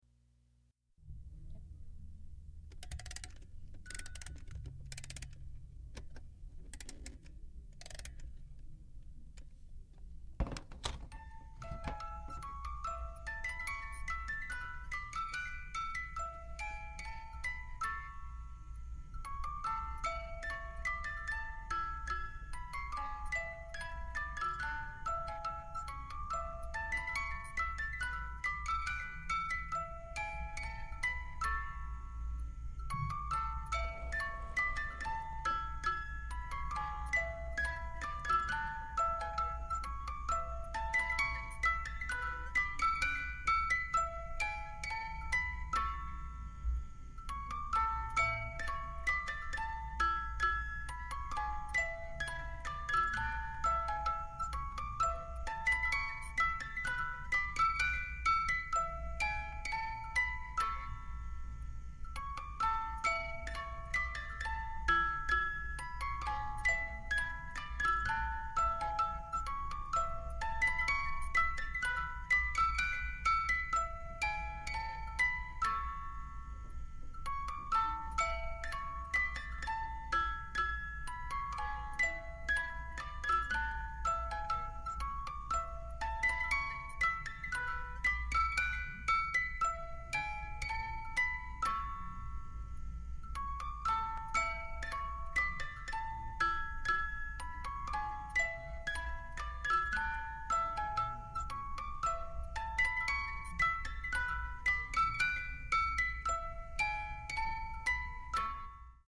Los invitamos a sumergirse en la nostalgia que guarda esta cajita negra que al darle cuerda y abrirla los espejos reflejan el movimiento de dos pequeños cisnes.
Lugar: Tuxtla Gutierrez, Chiapas; Mexico.
Equipo: Minidisc NetMD MD-N707, micrófono de construcción casera (más info)